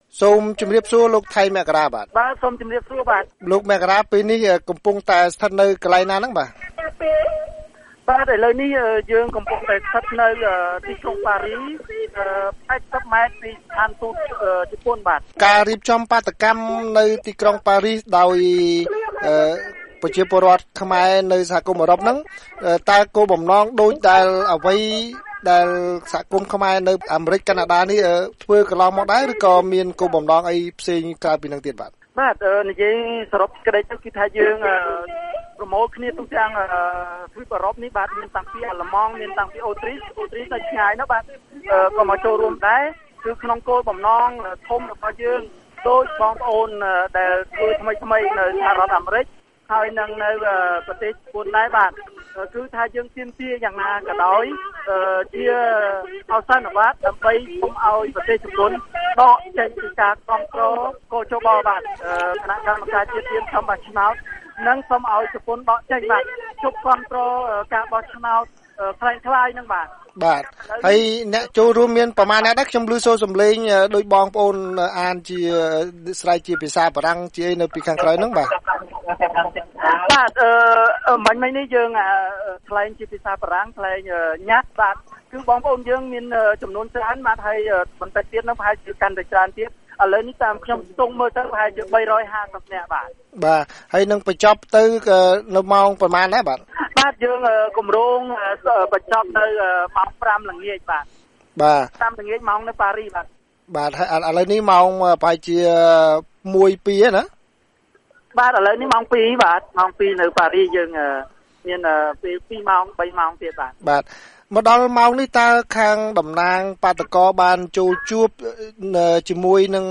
បទសម្ភាសន៍VOA៖ បាតុកម្មនៅបារាំងស្នើសុំជប៉ុនឈប់គាំទ្រគ.ជ.ប